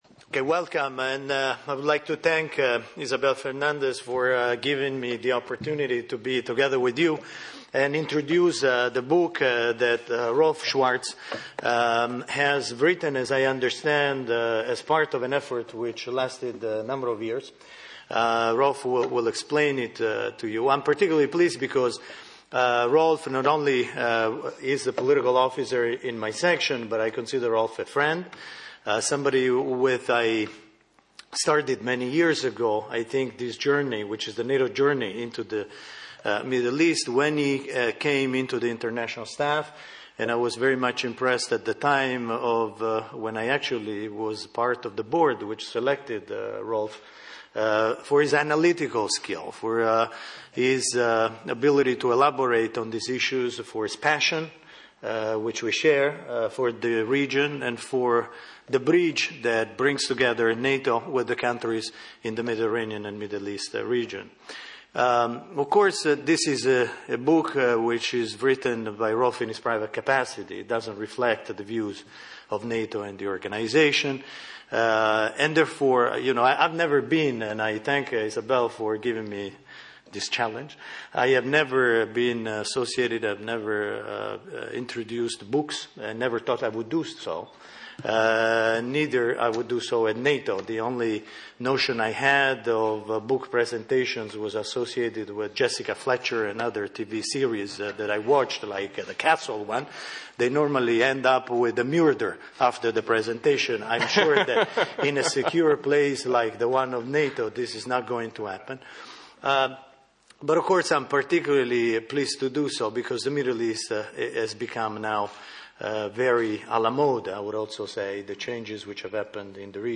Book presentation